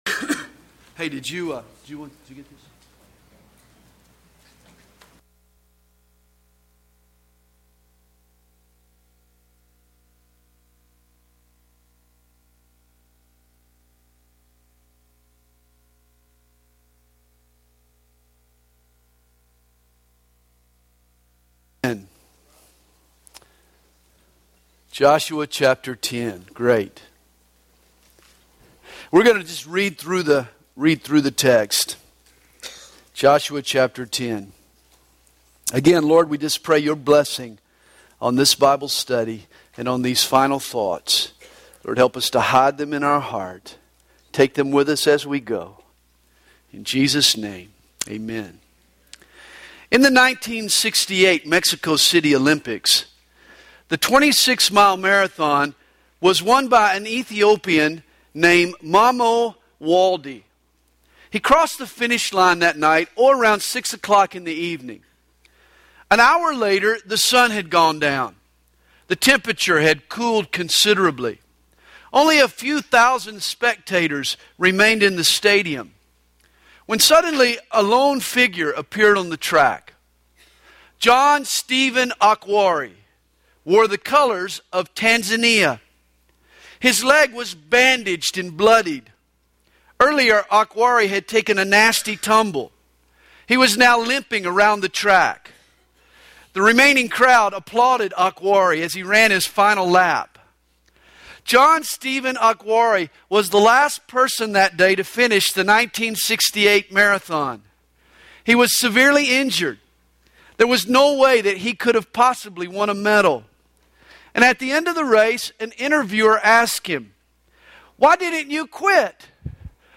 2010 DSPC Conference: Pastors & Leaders Date